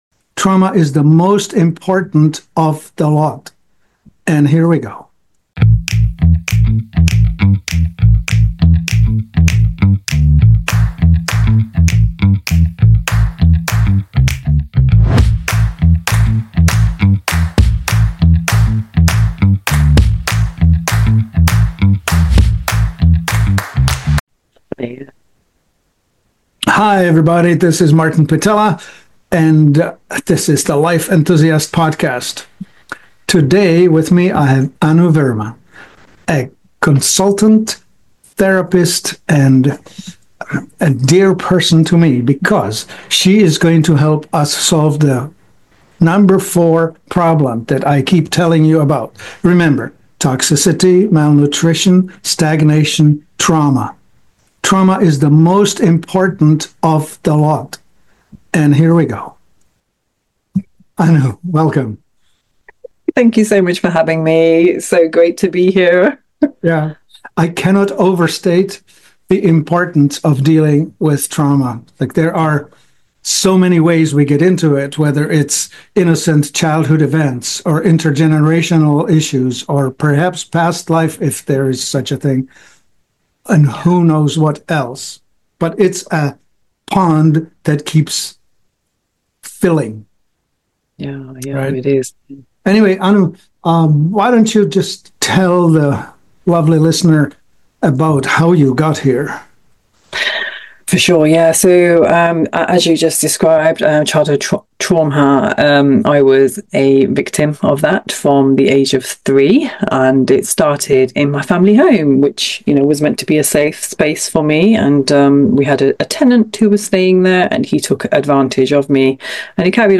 They break down practical ways to start the healing journey—like emotional regulation, reframing painful memories, and rebuilding trust in life. This is a conversation about hope, resilience, and the power we all have to move from surviving to thriving.